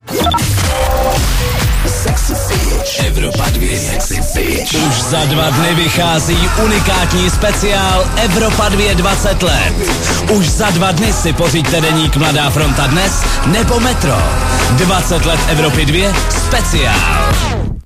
RÁDIOVÁ ANONCE: